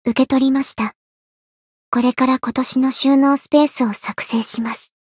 灵魂潮汐-阿卡赛特-春节（送礼语音）.ogg